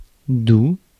Ääntäminen
France (Paris)